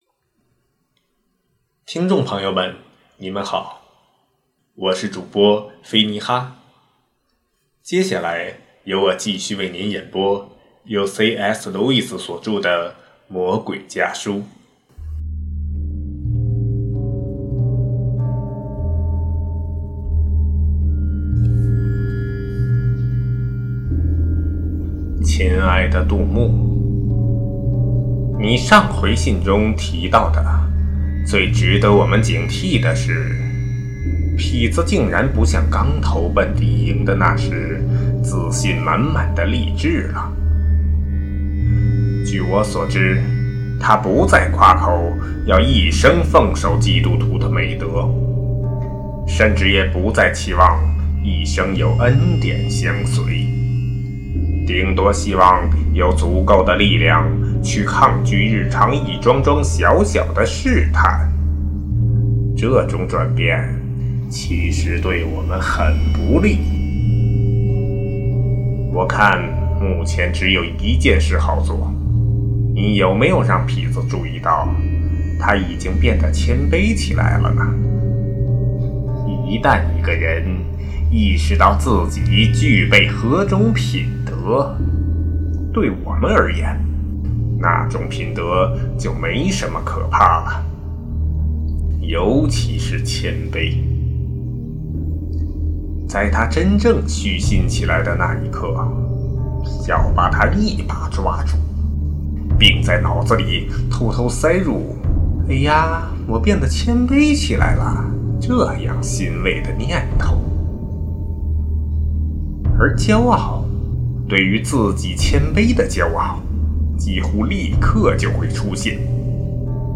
首页 > 有声书 | 灵性生活 | 魔鬼家书 > 魔鬼家书：第十四封书信